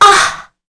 Kara-Vox_Damage_kr_02.wav